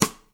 share-stick.wav